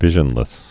(vĭzhən-lĭs)